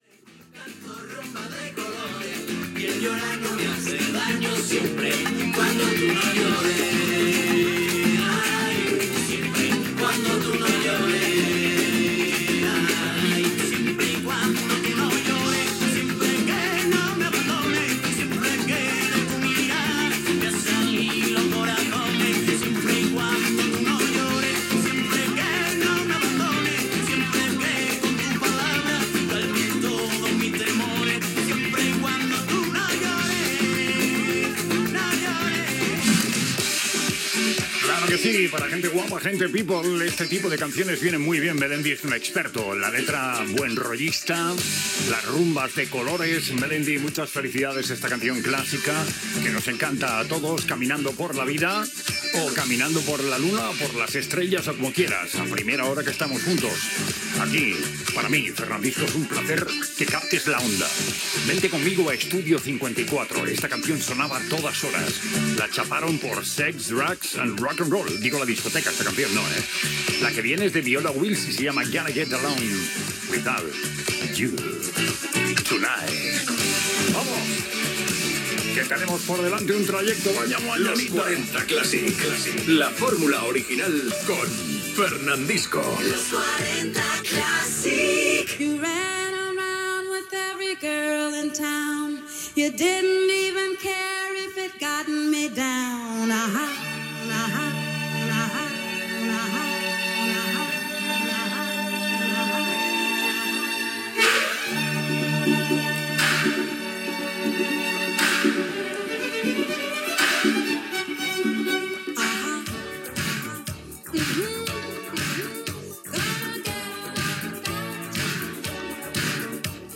Presentació de temes musicals de Fernandisco i indicatius de la cadena
Musical
FM